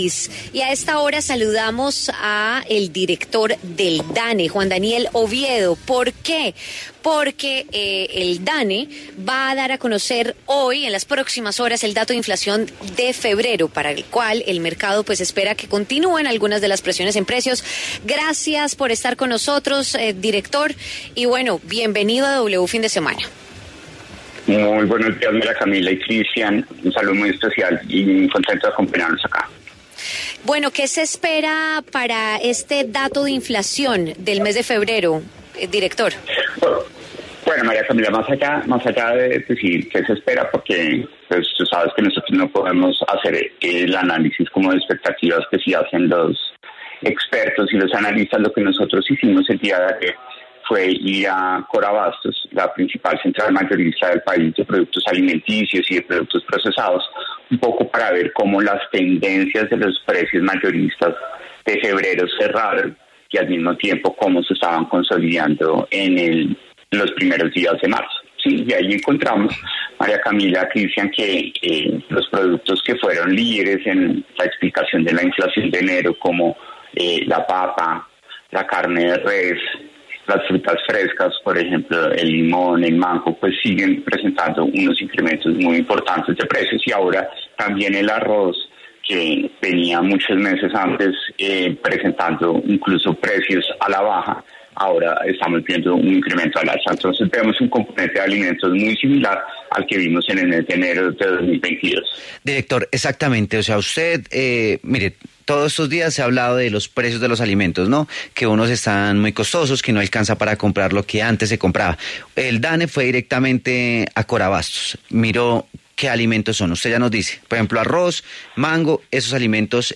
W Fin de Semana conversó con Juan Daniel Oviedo, director del DANE, quien hizo un balance sobre el comportamiento de las cifras de inflación en los últimos meses.